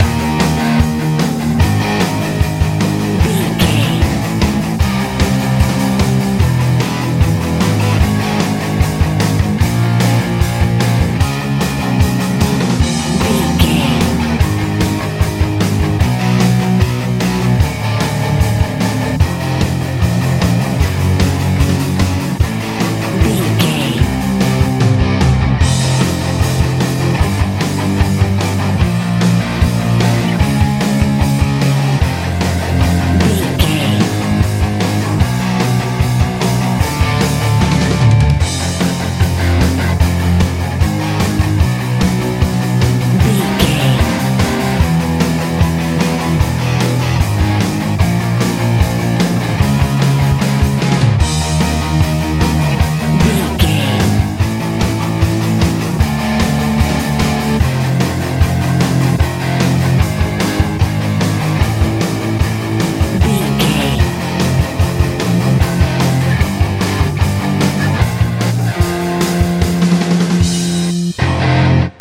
punk feel
Ionian/Major
E♭
driving
heavy
electric guitar
bass guitar
drums
aggressive